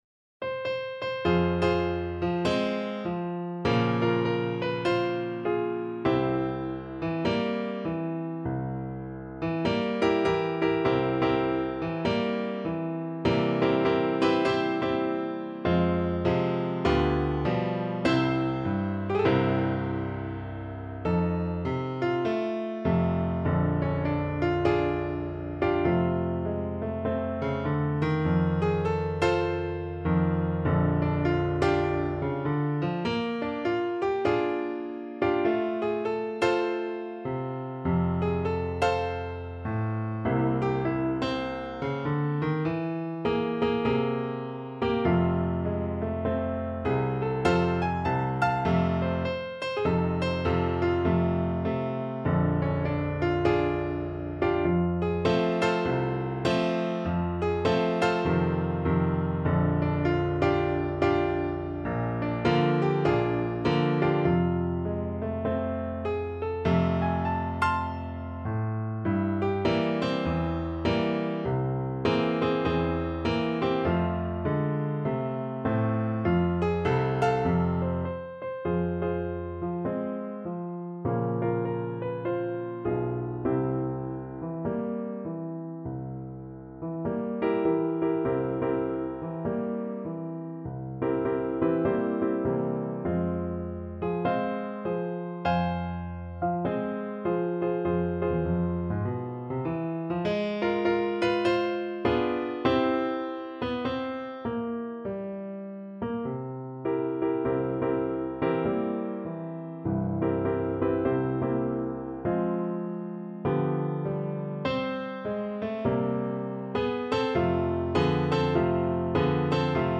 4/4 (View more 4/4 Music)
With a swing = c.100
Jazz (View more Jazz Percussion Music)